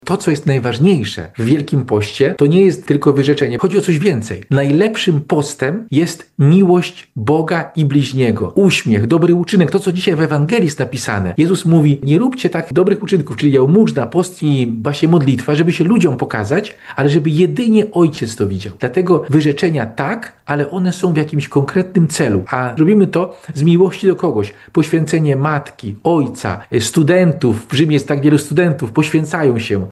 WIelki Post - rozmowa